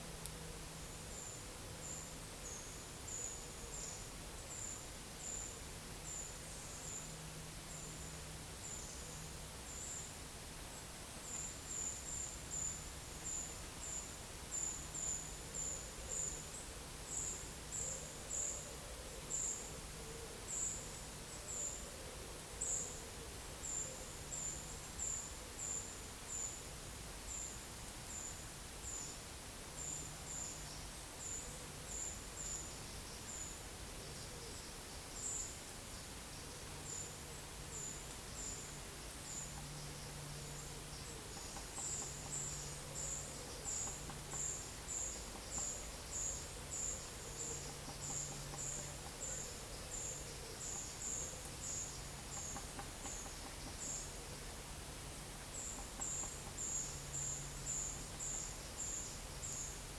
Monitor PAM
Certhia familiaris
Certhia brachydactyla